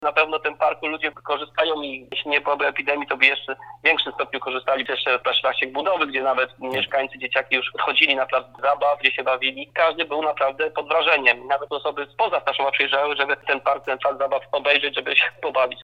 Z powodu obecnej sytuacji na oficjalnie otwarcie tego terenu będziemy musieli trochę poczekać powiedział burmistrz Leszek Kopeć.